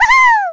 Voice clip from Donkey Kong 64